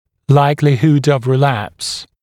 [‘laɪklɪhud əv rɪ’læps][‘лайклихуд ов ри’лэпс]вероятность рецидива